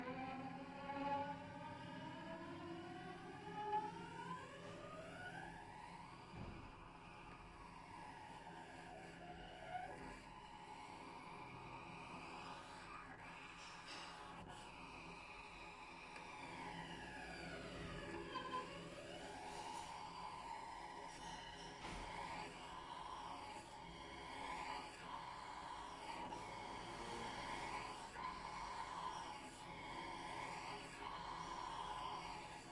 大提琴 " 大提琴A3轻声细语gliss - 声音 - 淘声网 - 免费音效素材资源|视频游戏配乐下载
在大提琴的A3弦上轻轻地演奏滑音，用左手轻轻地按，从而使声音变小。这就像一个耳语的声音，我们听到A3弦的谐音。它以一个完整的滑音开始，从弦的最低音域到高音域，然后在高音域移动。用索尼PCM D50短距离录制的。